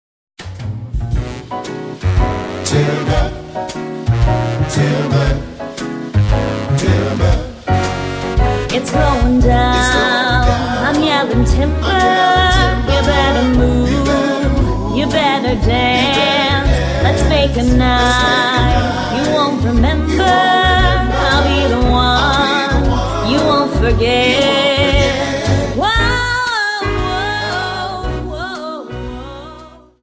Dance: Slowfox